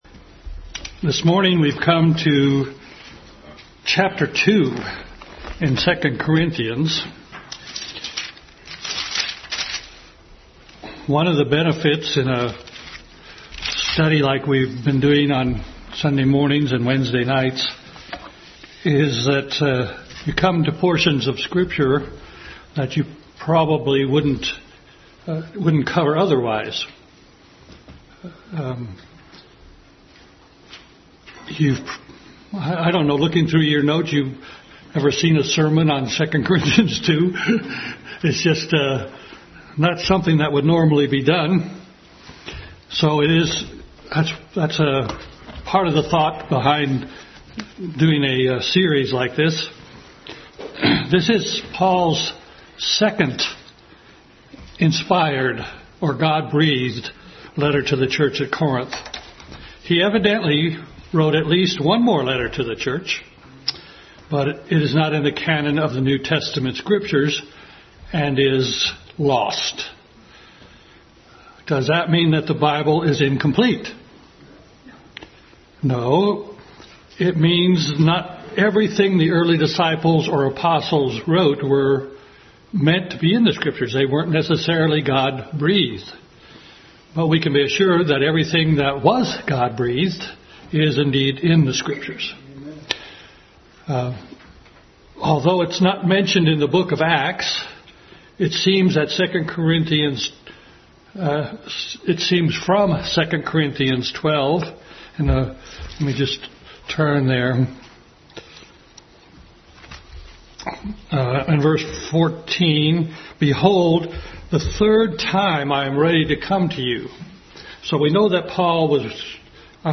Adult Sunday School Class continued study in 2 Corinthians.